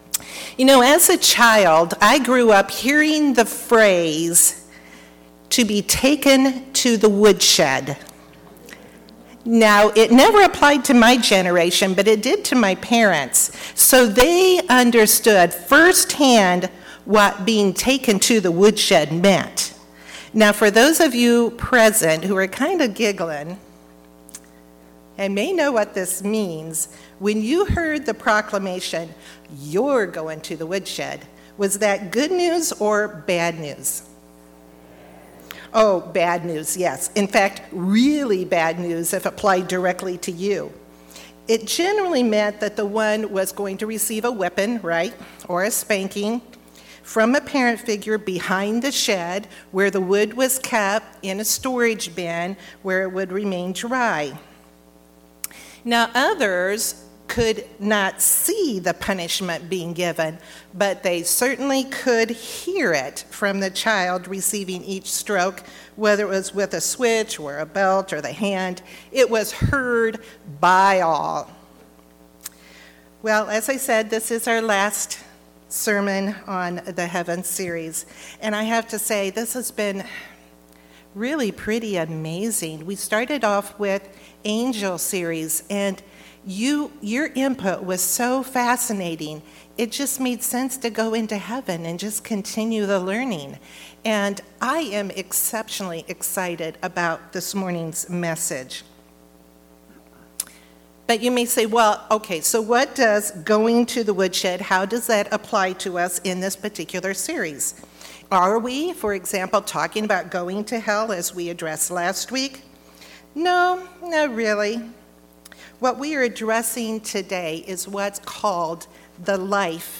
2019 Lincolnshire Sermons